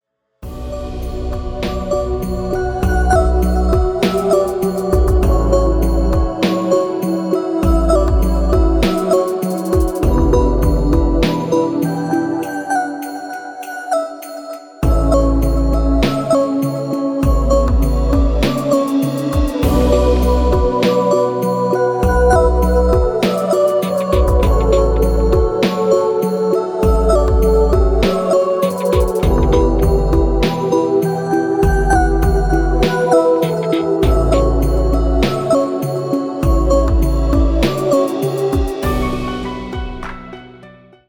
атмосферные
спокойные
без слов
красивая мелодия
Electronica
космические
Завораживающие